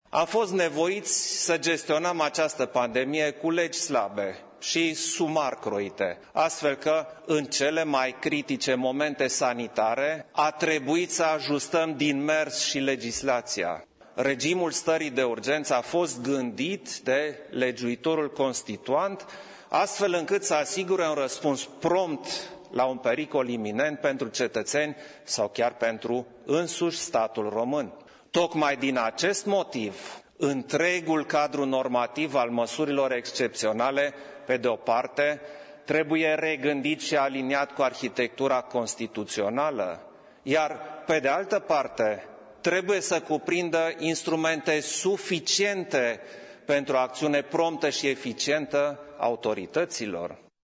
Preşedintele a declarat, azi, că autorităţile au fost nevoite să gestioneze pandemia de COVID-19 cu legi ‘slabe’ şi ‘sumar croite’, fiind necesar să ajusteze legislaţia ‘din mers’: